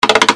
cartoon36.mp3